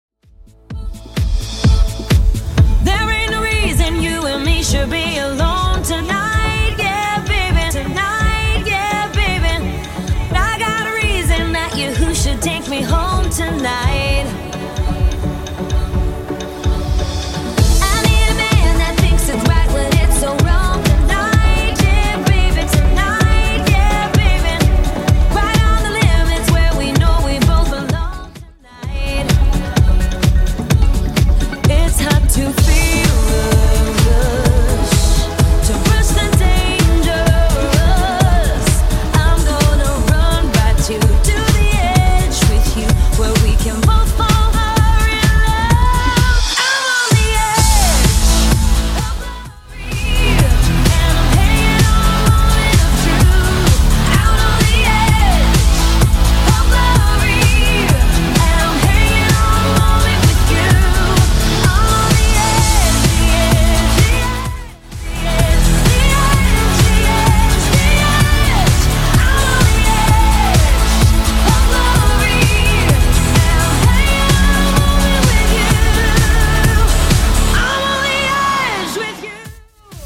Genre: 90's
BPM: 83